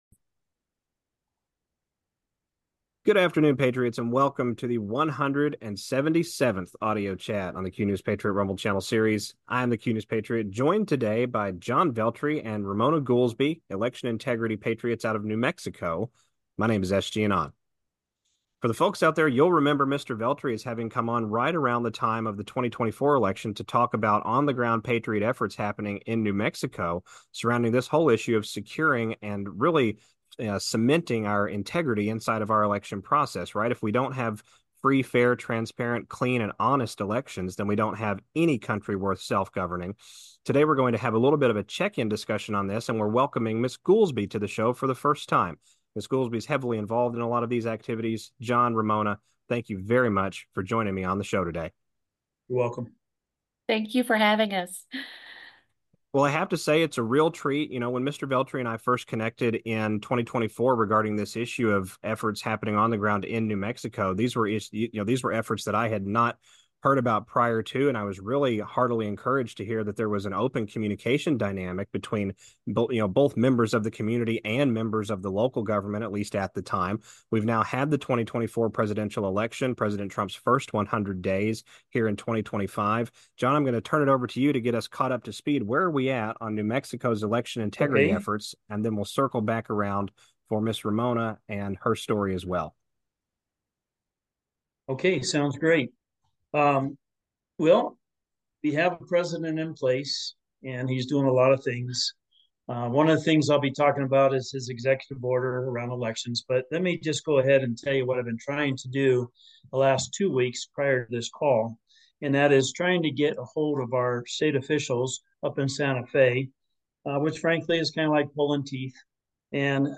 AUDIO CHAT 177